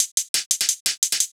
Index of /musicradar/ultimate-hihat-samples/175bpm
UHH_ElectroHatB_175-04.wav